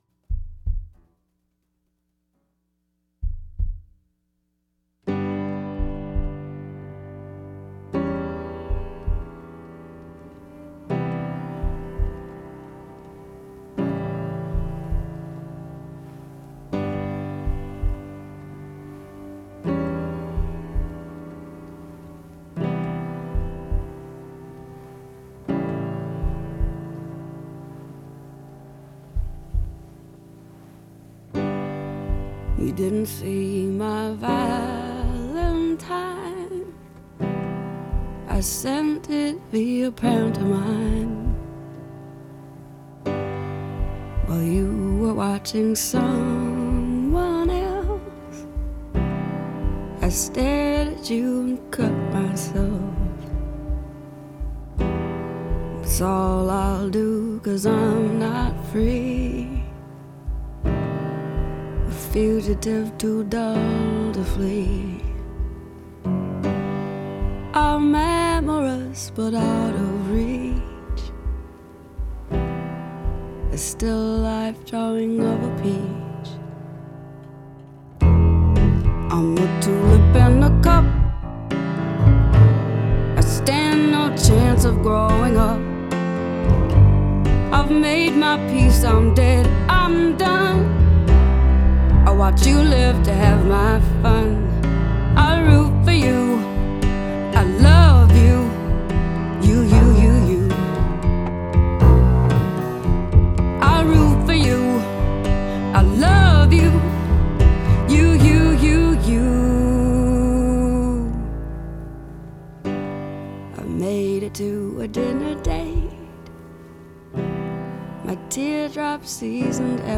Her love songs are heartbroken and will remain timeless.
By way of repetitive lines and words.